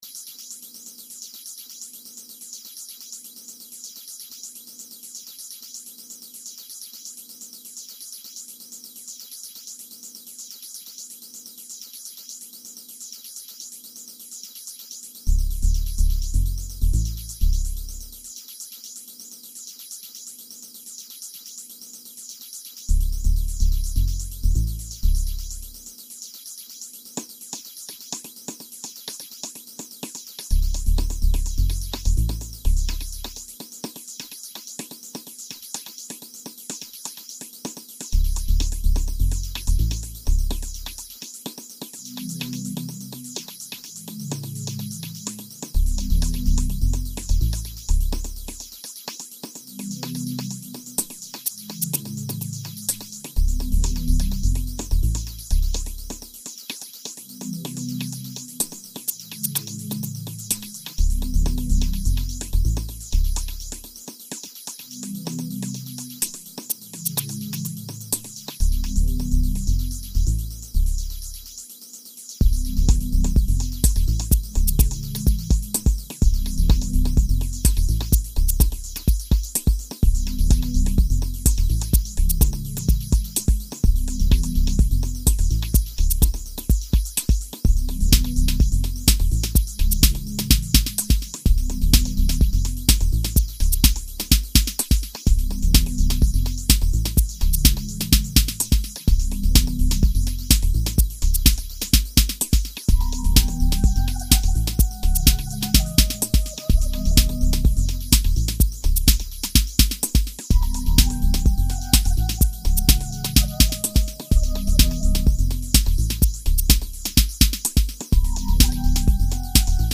dance/electronic
House
Breaks & beats
IDM